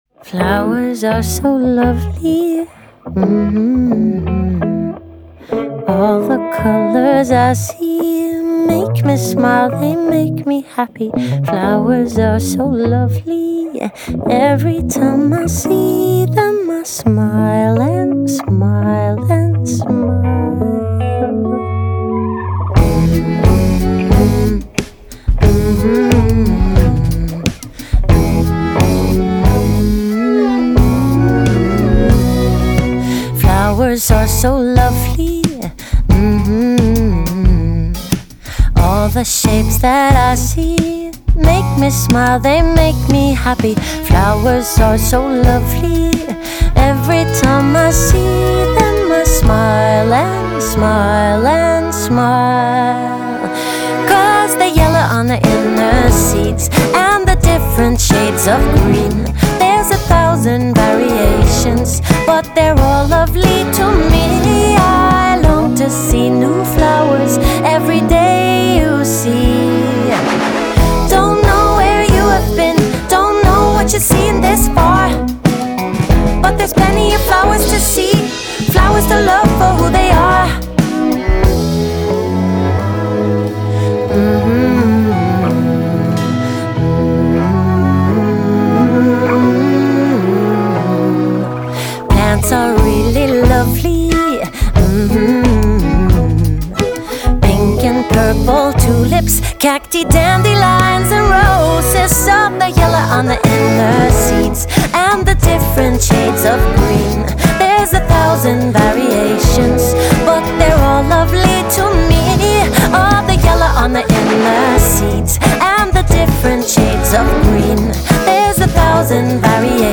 Scandinavian singer-songwriter